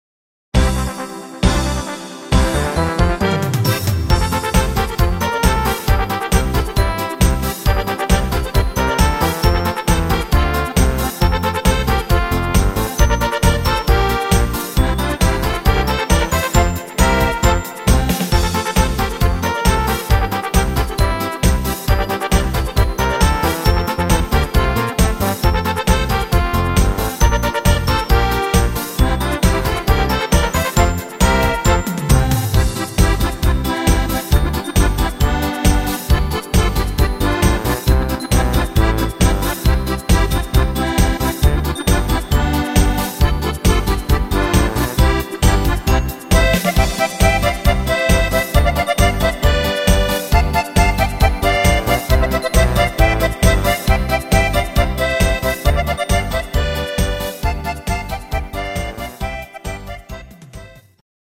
instr. Trompete